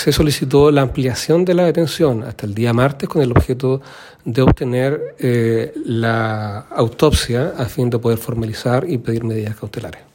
Tras la detención, el presunto autor del homicidio fue presentado ante el Juzgado de Garantía de Valdivia, sin embargo, la formalización de cargos quedó programada para este martes, a la espera del informe de autopsia que debe elaborar el Servicio Médico Legal, tal como indicó el fiscal Carlos Bahamondes.